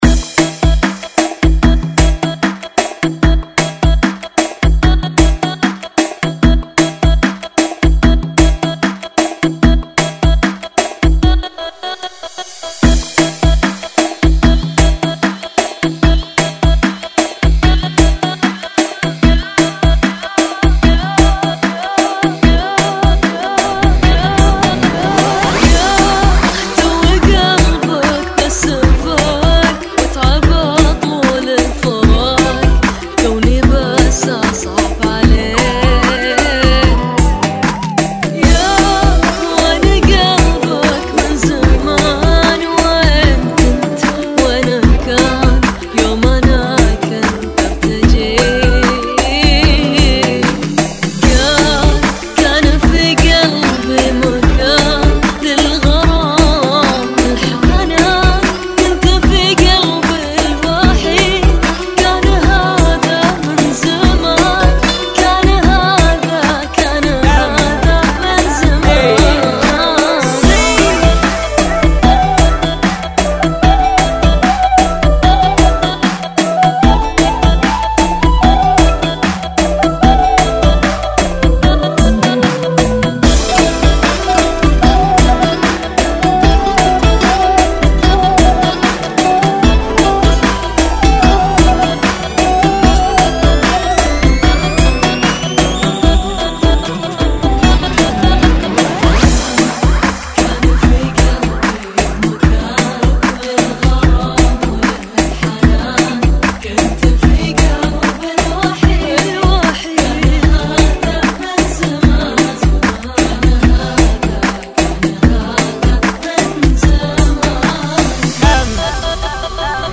75 bpm
Funky